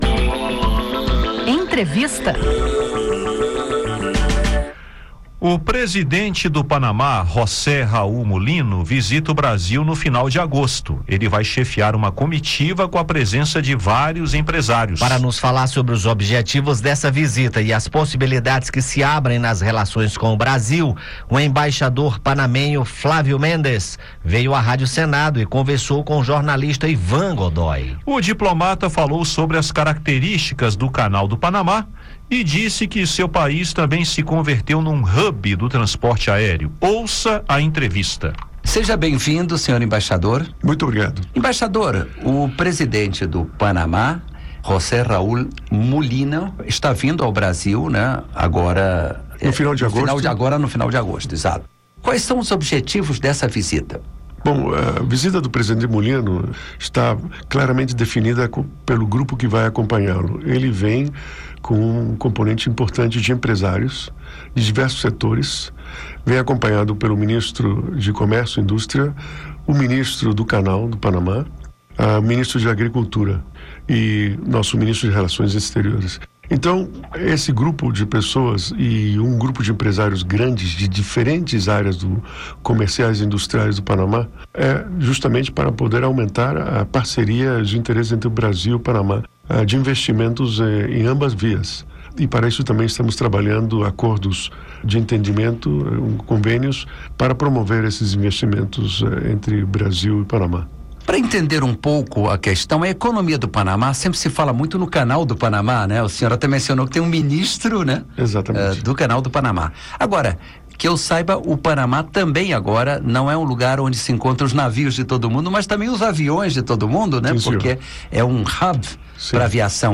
O embaixador panamenho Flavio Méndez conversou com o jornalista